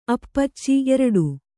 ♪ appacci